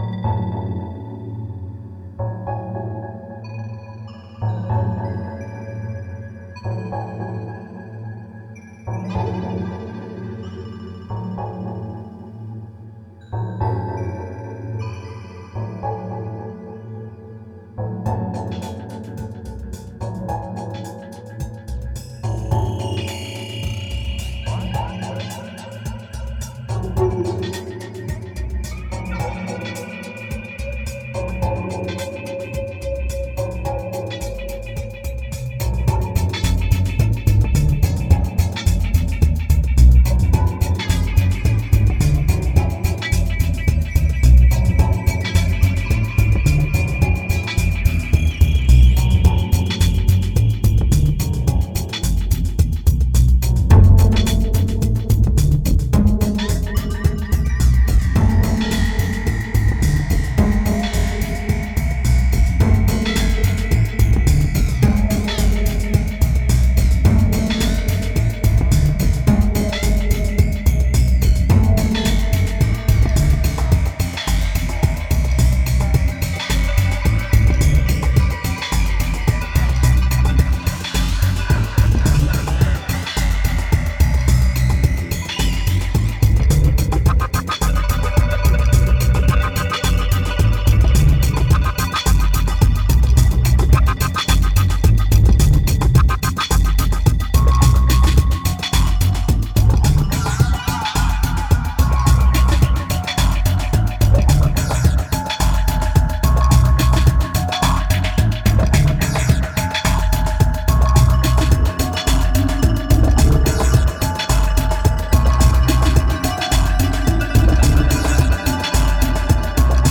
2216📈 - -15%🤔 - 108BPM🔊 - 2011-10-21📅 - -140🌟